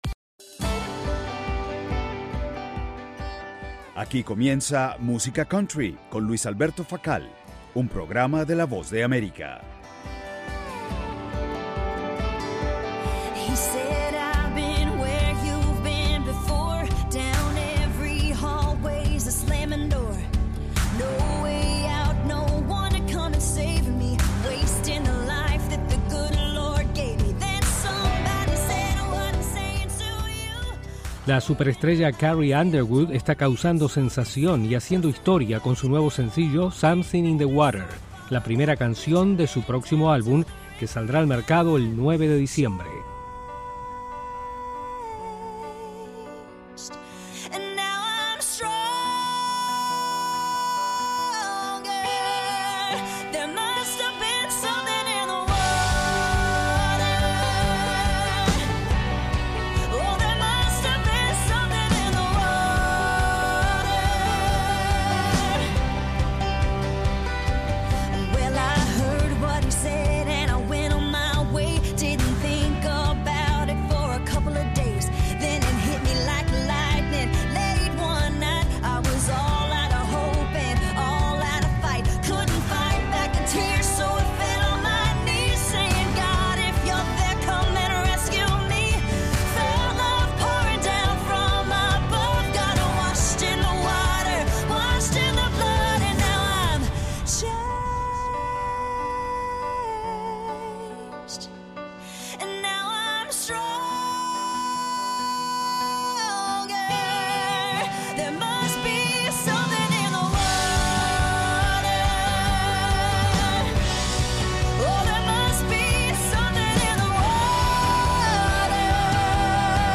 Música Country